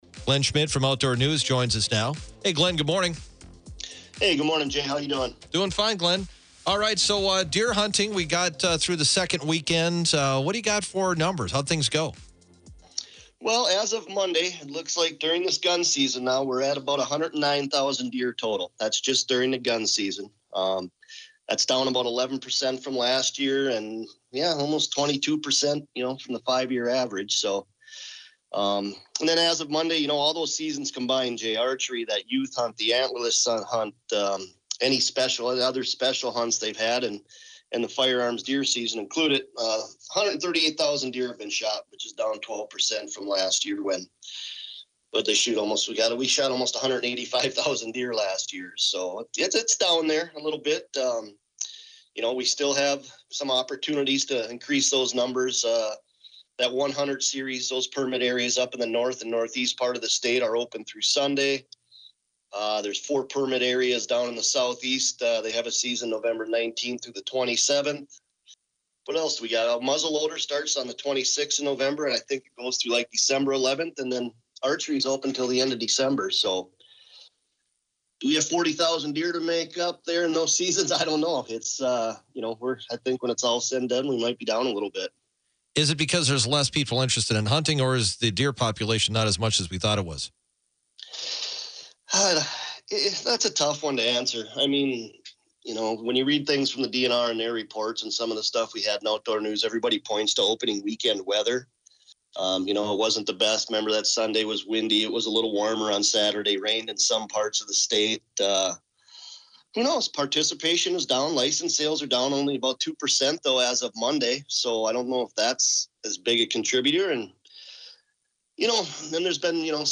dialog